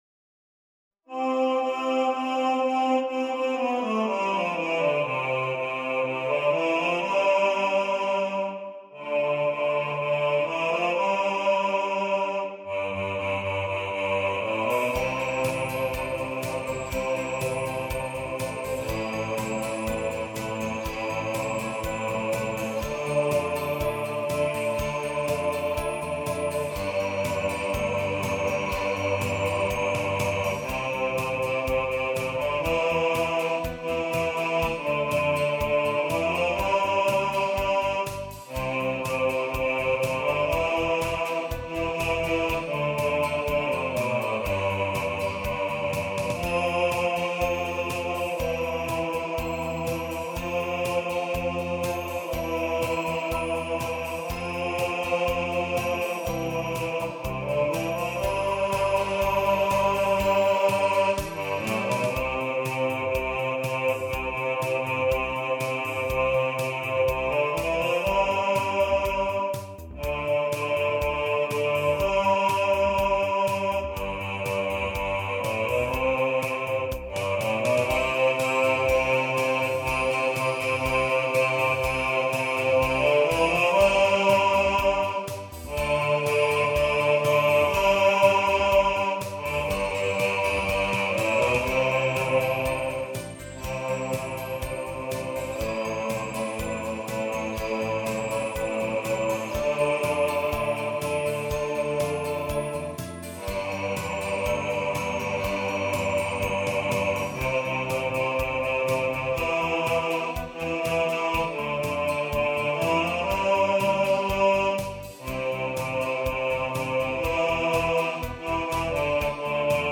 Super Trouper – Bass | Ipswich Hospital Community Choir
Super-Trouper-Bass-1.mp3